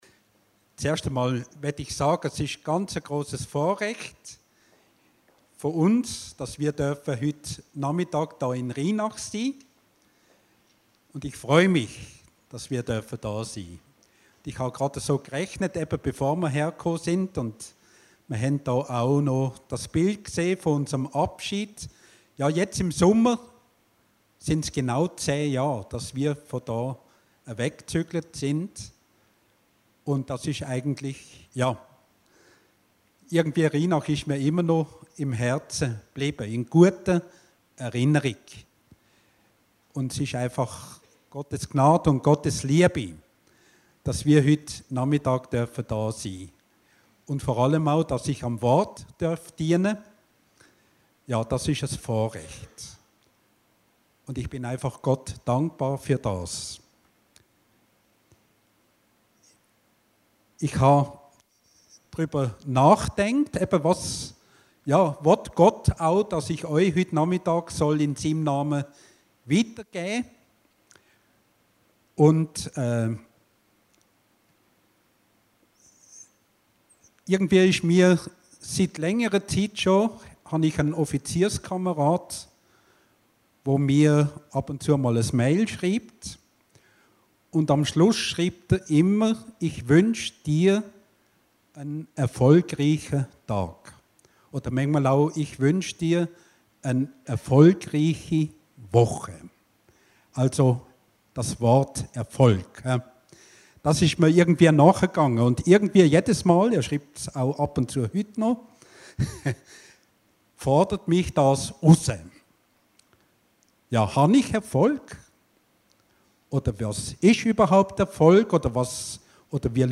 Predigten Heilsarmee Aargau Süd – Unser Dienst für Jesus Christus